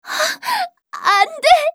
archer_f_voc_social_lose_a.mp3